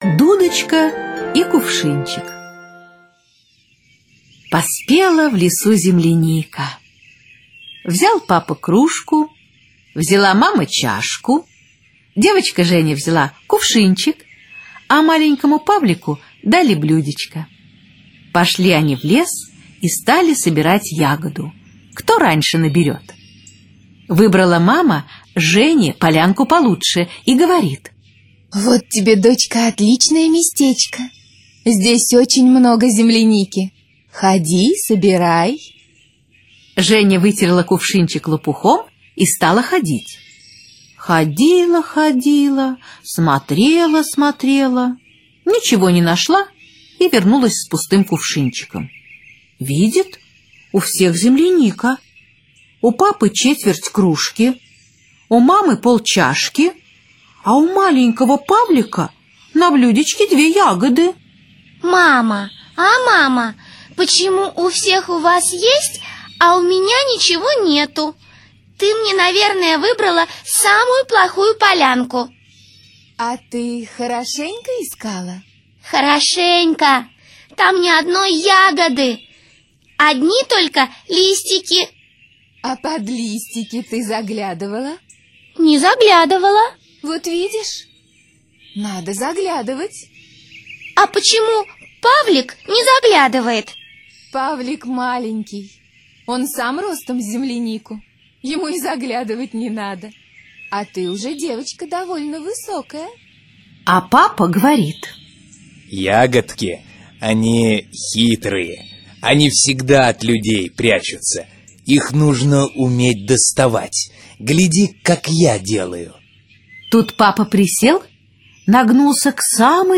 Слушайте Дудочка и кувшинчик - аудиосказка Катаева В.П. Сказка о том, как девочка Женя, ее родители и младший брат Павлик ходили в лес за земляникой.